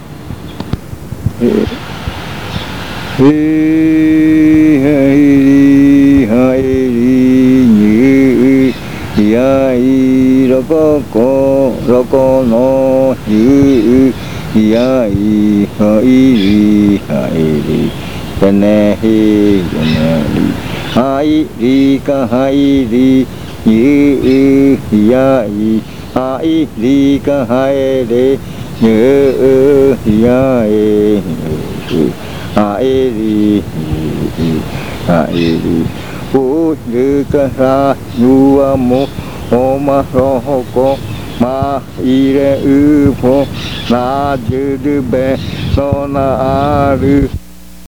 This chant does not have a fixed time for the Murui singer. This chant is related to greeting and farewell.